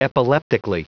Prononciation du mot epileptically en anglais (fichier audio)
epileptically.wav